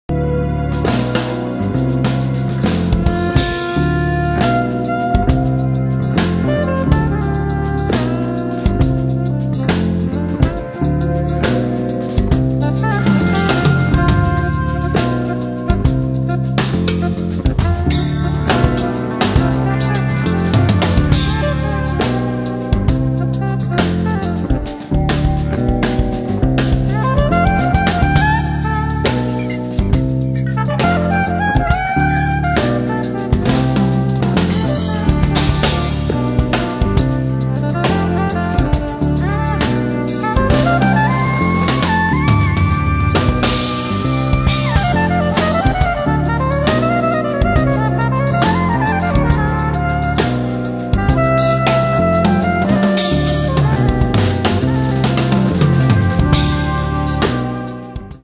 progr. Keyb. Perc. Bass/Guitar
Sax Soprano
Vocal
Guitar
Keyboards - el. piano
Percussion